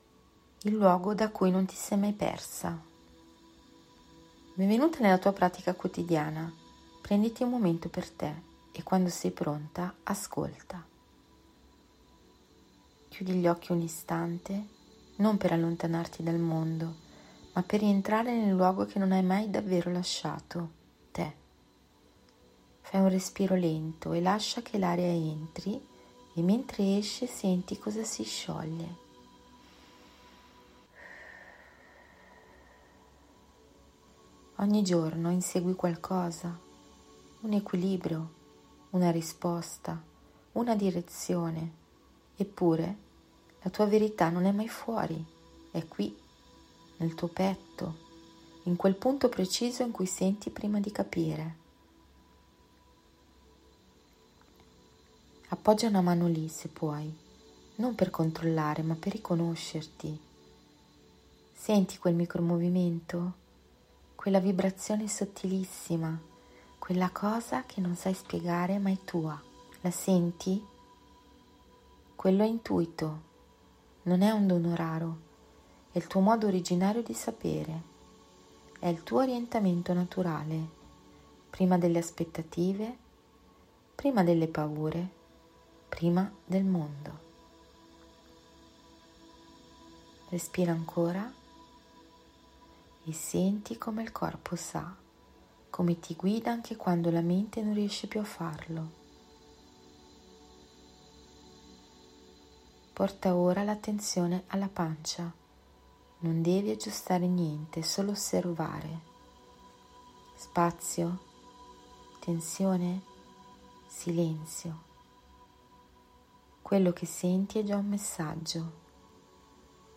E lasciati guidare dalla mia voce.